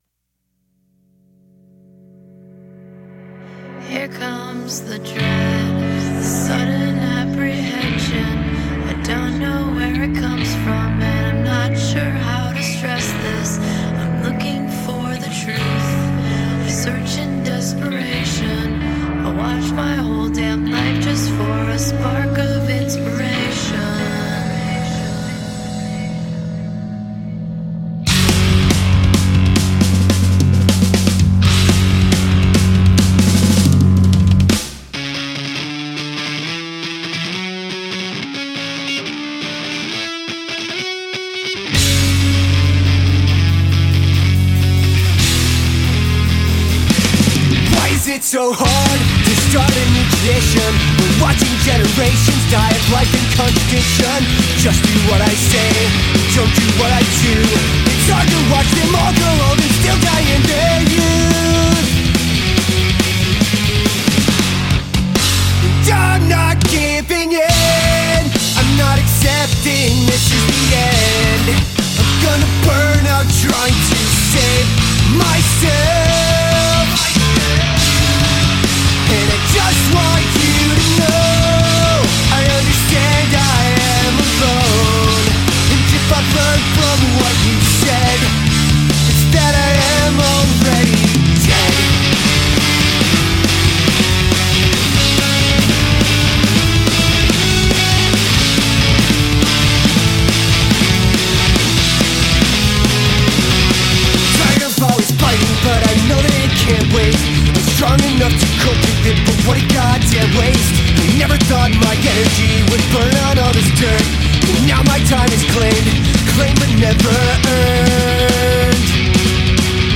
pop-punk trio